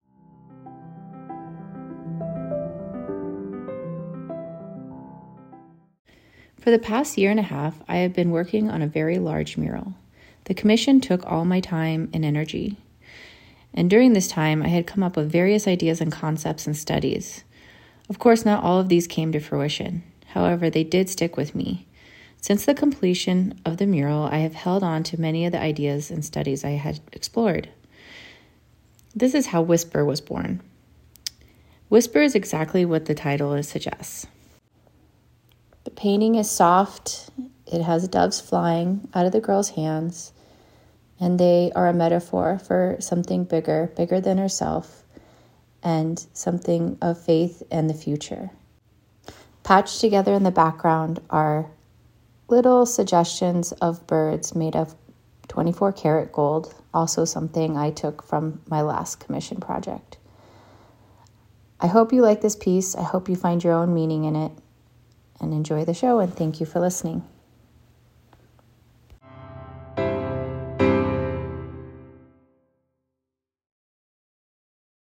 Discover short audio reflections from the artists for Flourishing with Beauty.